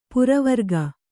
♪ puravarga